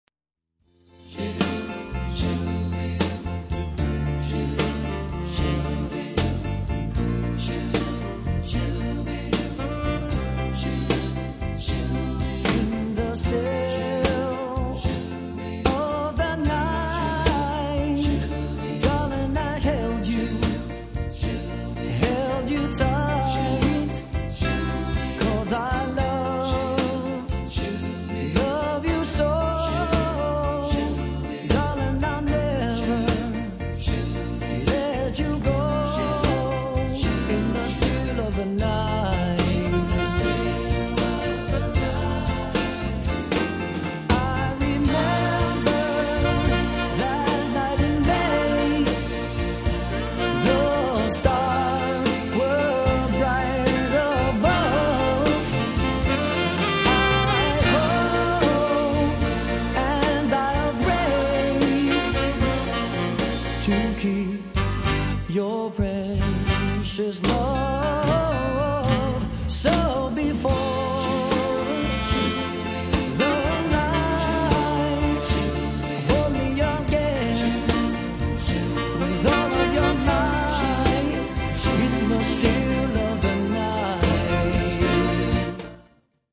50's/Ballad